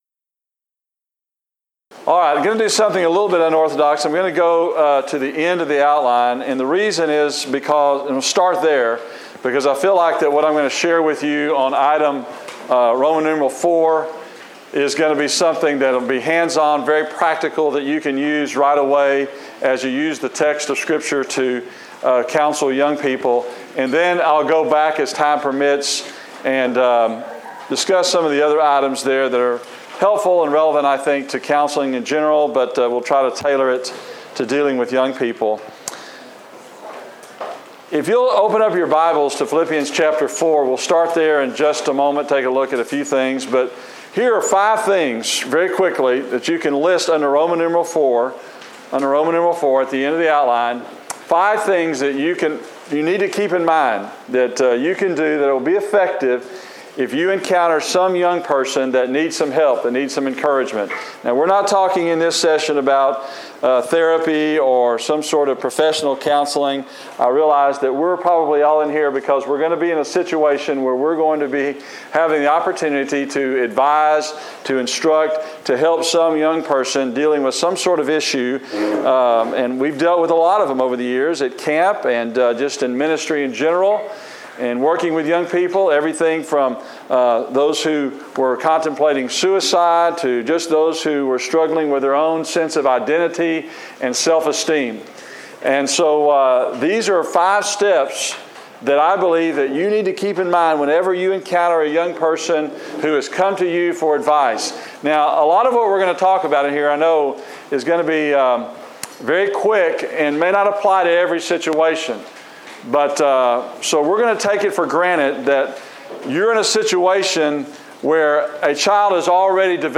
Event: Discipleship U 2016
lecture